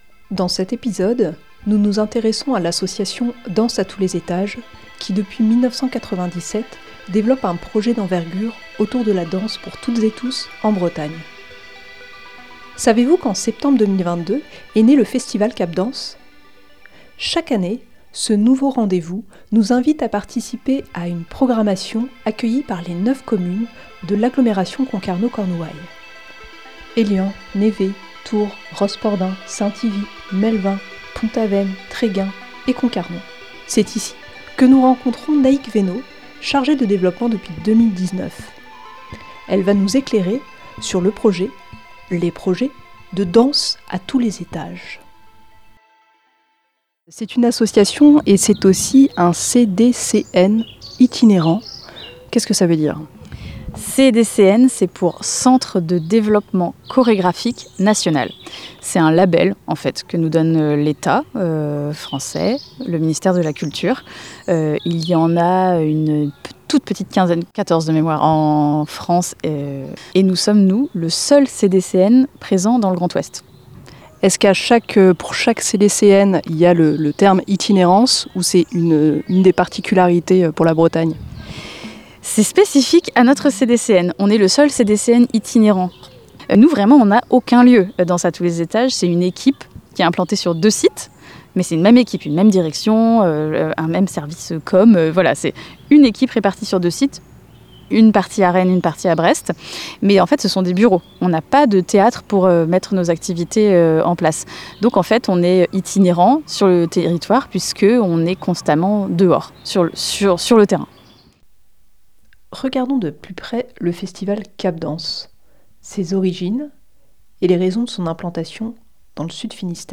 Ce reportage présente le projet porté par l'association devenue Centre de Développement Chorégraphique National itinérant (CDCN).